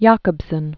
(yäkəb-sən), Roman 1896-1982.